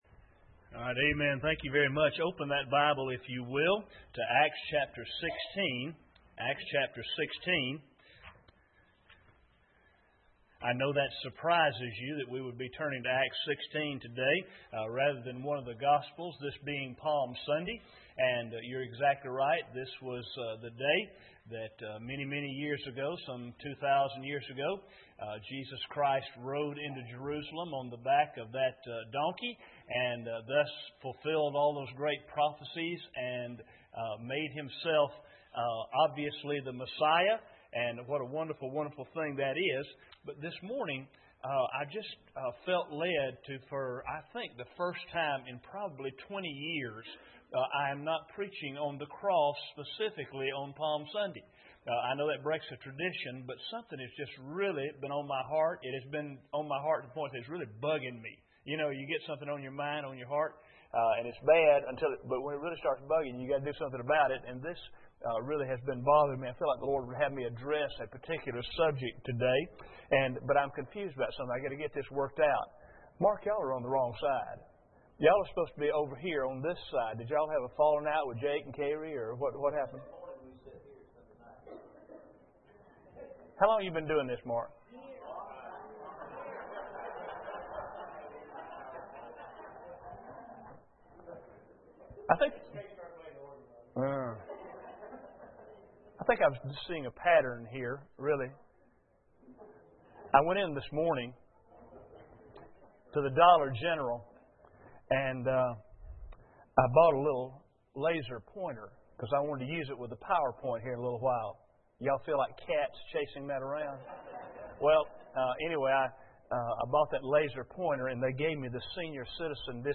Passage: Acts 16:31 Service Type: Sunday Morning